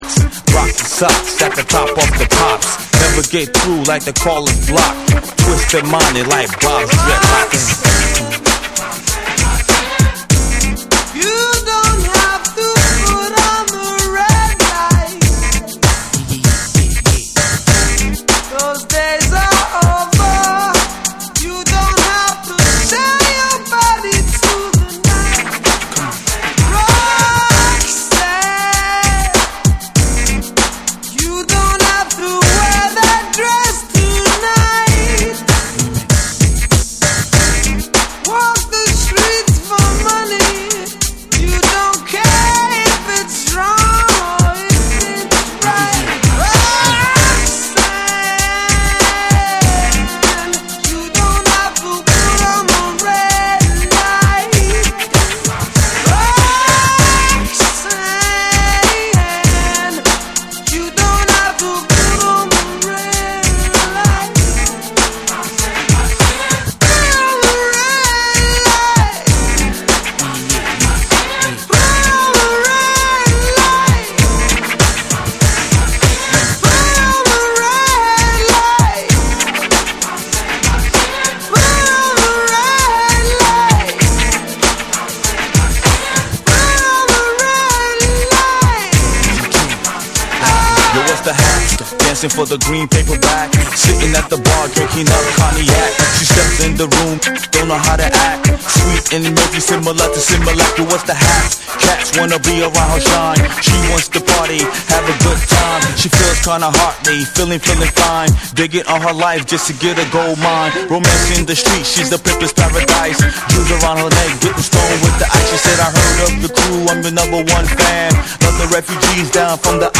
オリジナルの魅力を活かしつつ、ヒップホップ〜ブレイクビーツ〜ハウスの視点でアップデートされたクロスオーバーな一枚。
NEW WAVE & ROCK / BREAKBEATS / TECHNO & HOUSE